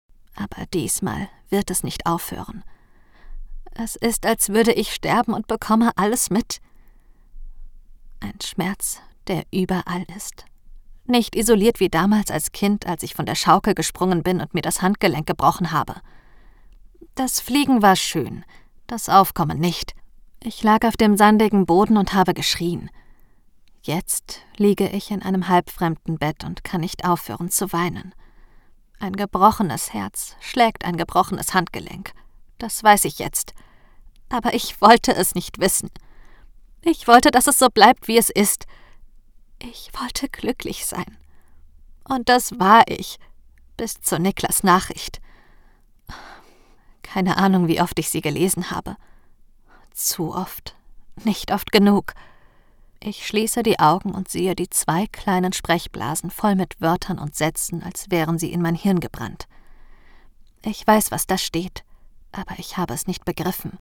Sprecher und Stimmen von Young Adult anhören, kostenlos Angebot einholen, günstig aufnehmen.
Werbung ITB